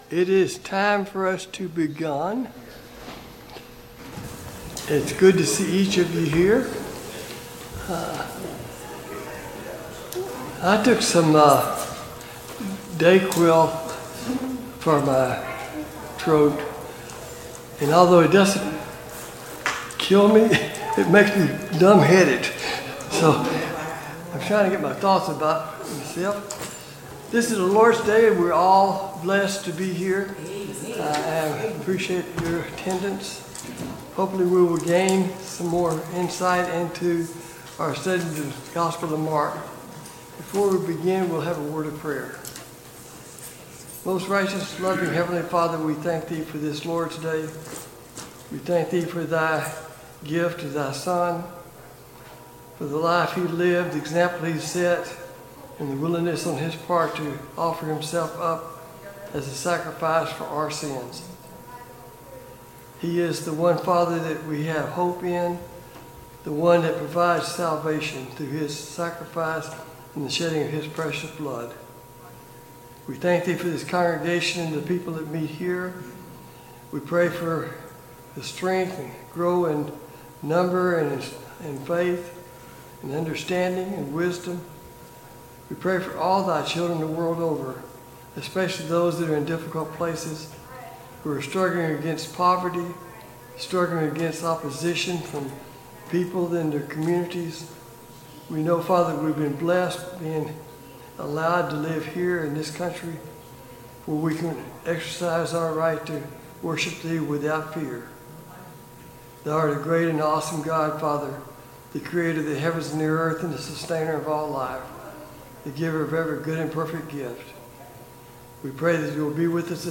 Sunday Morning Bible Class « 10.